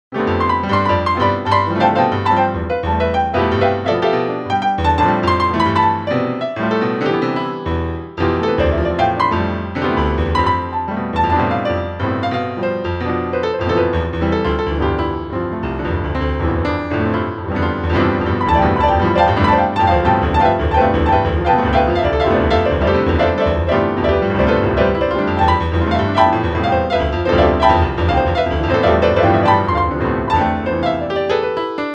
Hi, I would love to share with you this short piece I've composed It's an incomplete work composition slightly improvised